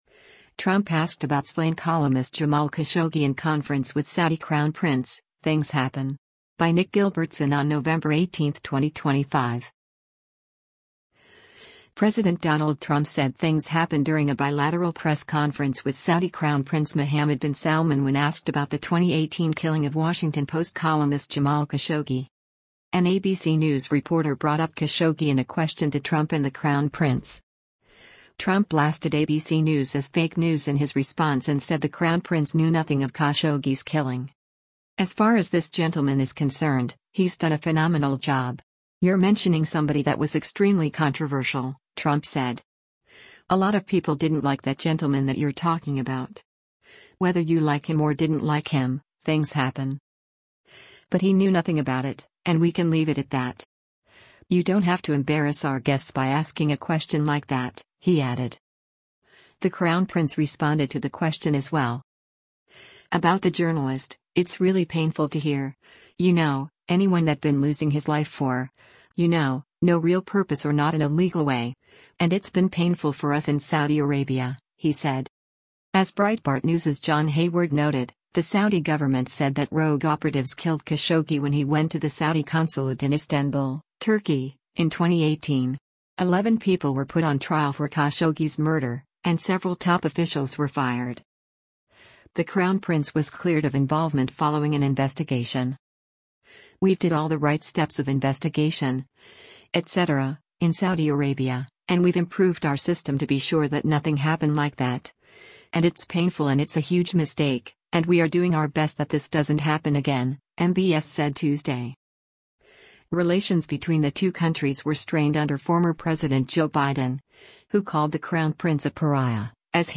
President Donald Trump said “things happen” during a bilateral press conference with Saudi Crown Prince Mohammed bin Salman when asked about the 2018 killing of Washington Post columnist Jamal Khashoggi.
An ABC News reporter brought up Khashoggi in a question to Trump and the crown prince.
The crown prince responded to the question as well.